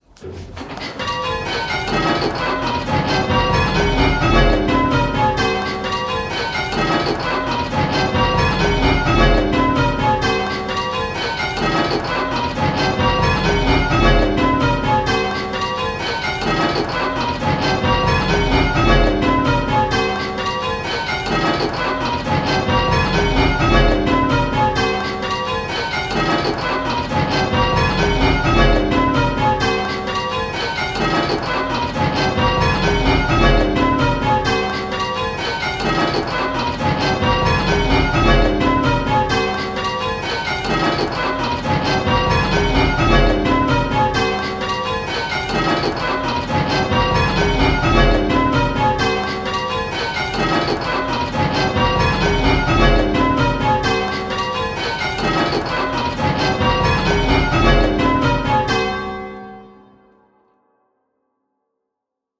Listen to each clip and choose which bell is ringing early… the faults have been randomly placed, so there is no pattern and there might be consecutive clips that have the same fault!
Click the play button and listen to the rhythm of the 10 bells…. the rhythm will sound a bit lumpy!
Rounds-10-6E.m4a